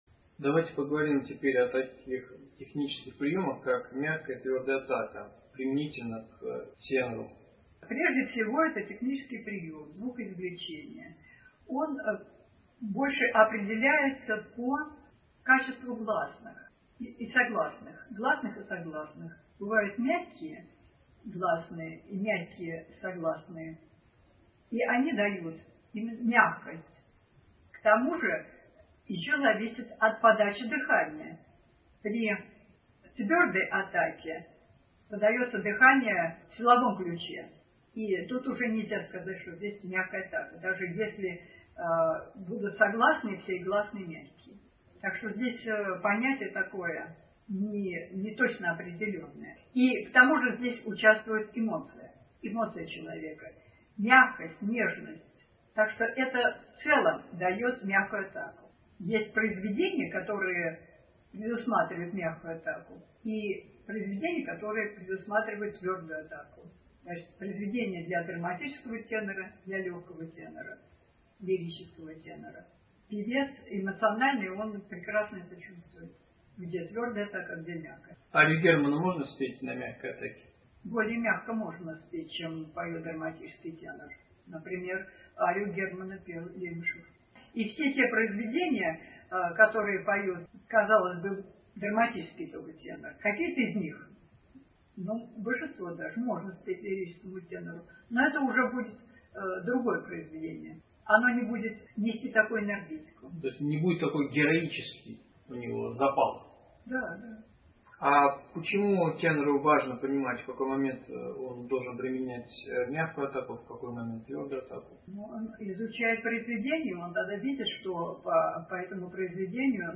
vocal_technique-6_5_tenor.mp3